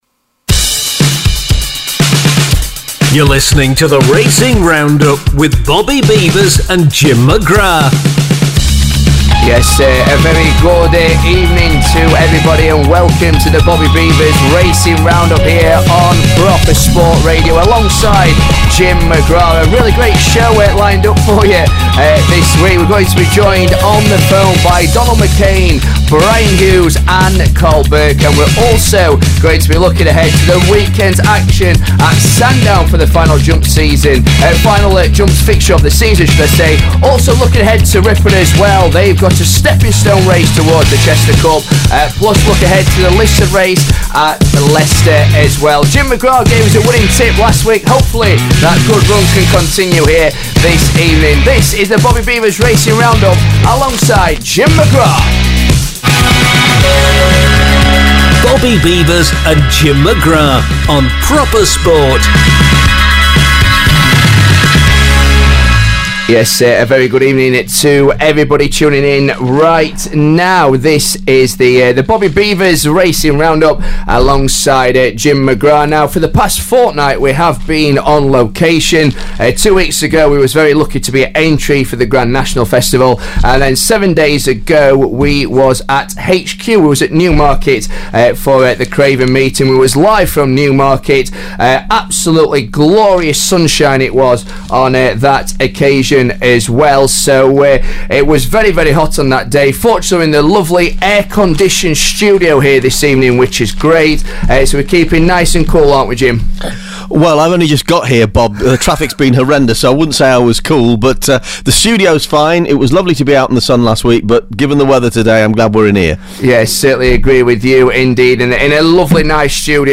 plus they are joined on the phone